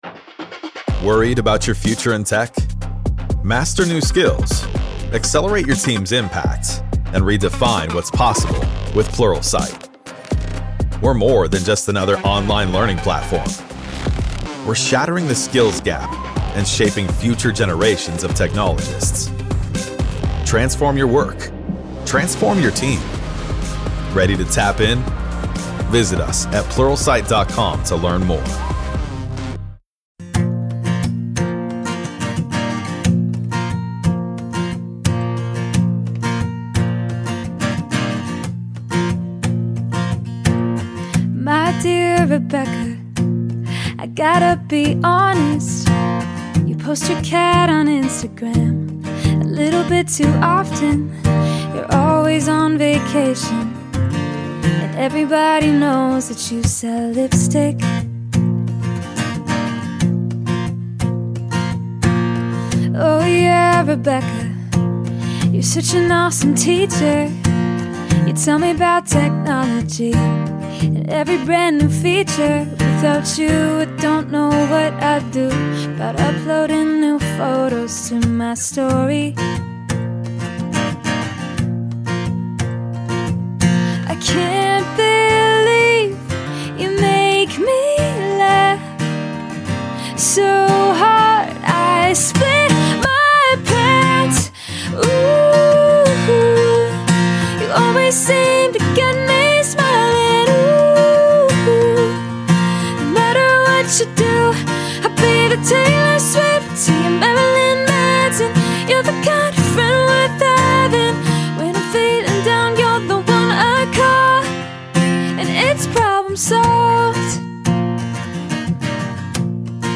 AND we sang several times.